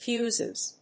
発音
• / ˈfjuzʌz(米国英語)
• / ˈfju:zʌz(英国英語)